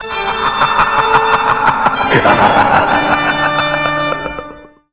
gameover.wav